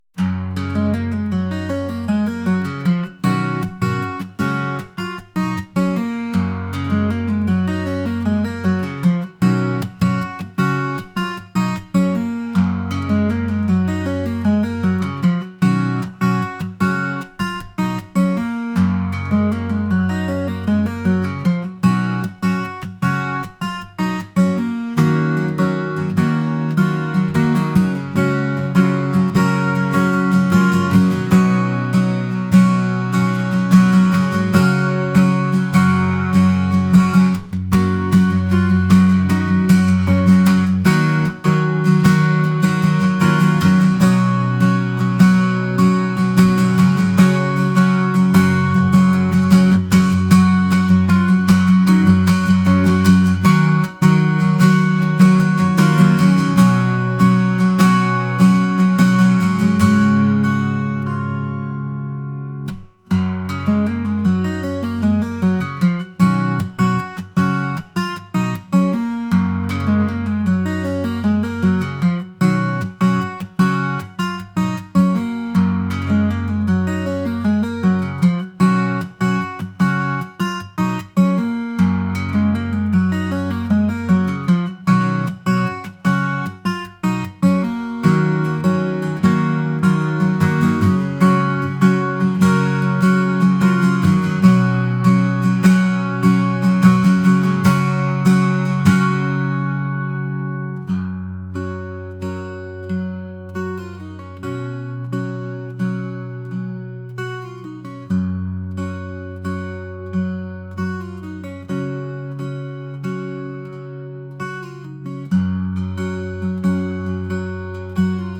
acoustic | indie | rock